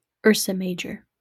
Pronunciation/ˌɜːrsə ˈmər/
UR-sə MAY-jər,